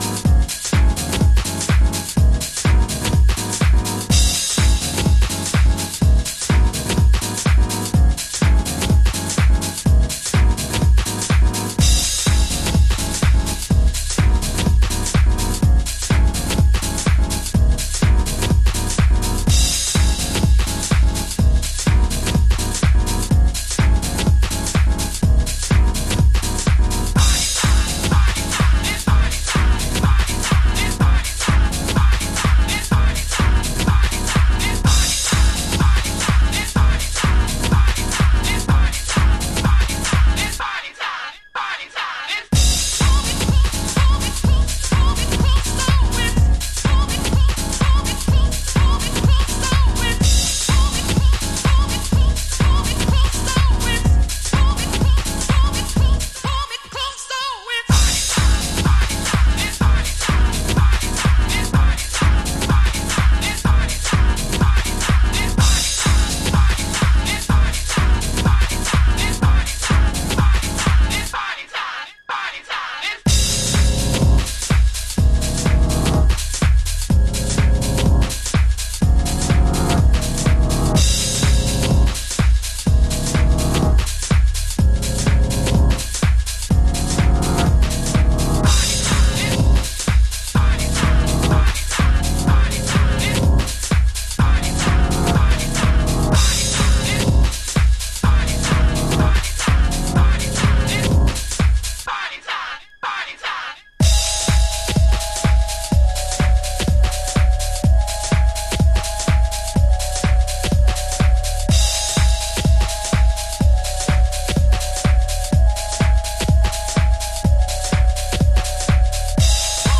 Detroit House / Techno
ザ・デトロイトハウスな3トラックス。